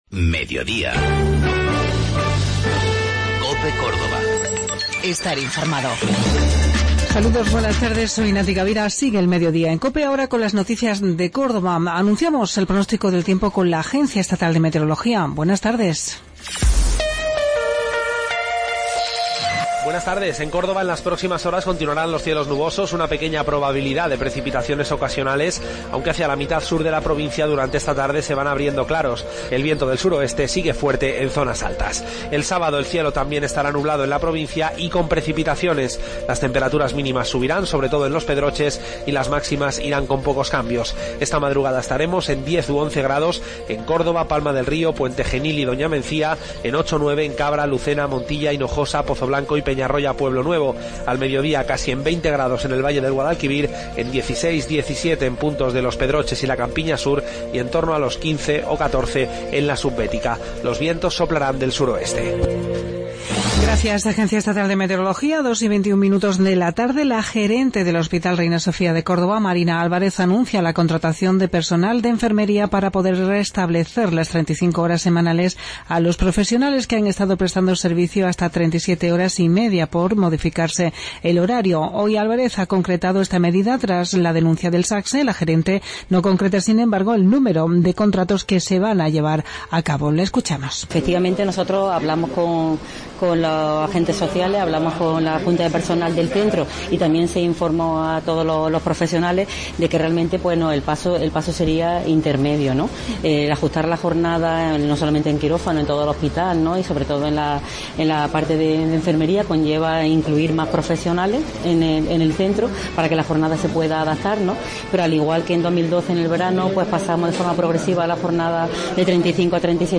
Mediodía en Cope. Informativo local 3 de Febrero 2017